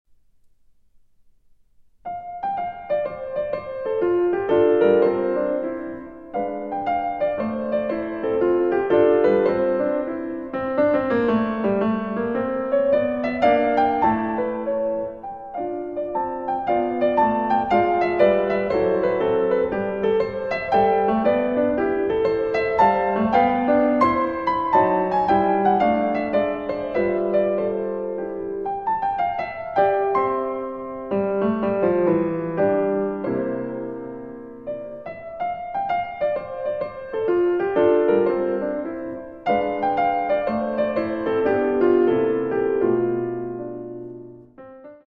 Allegro brioso